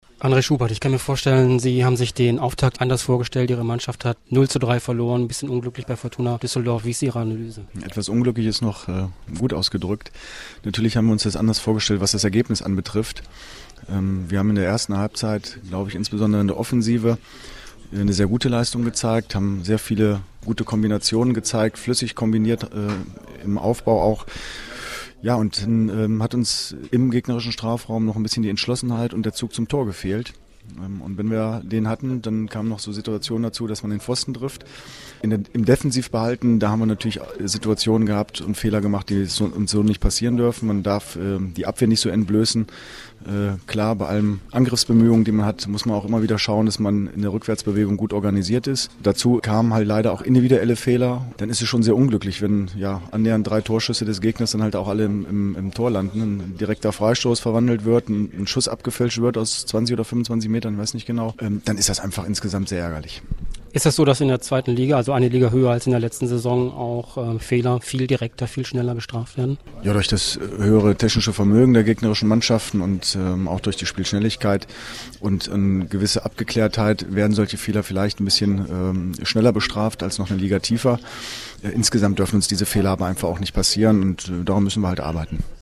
Audio-Kommentar von Chef-Trainer Andre Schubert zum Spiel